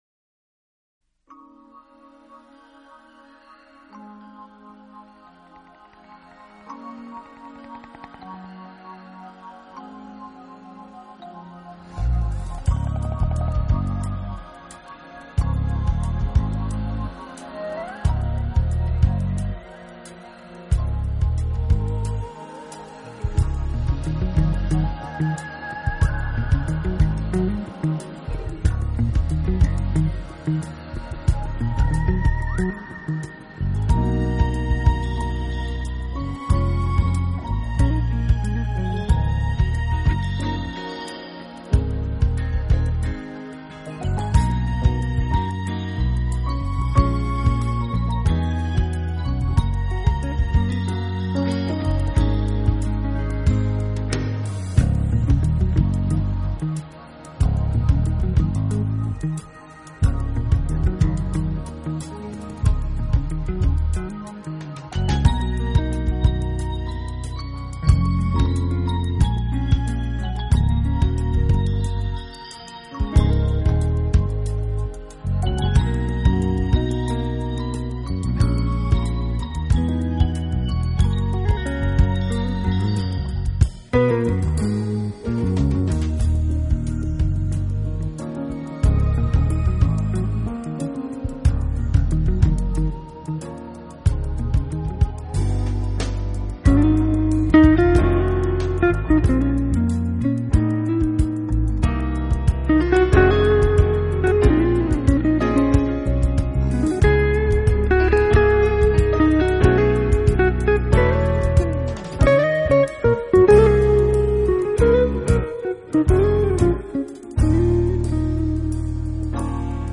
专辑类型： JAZZ
遥遥的键盘音色旋绕中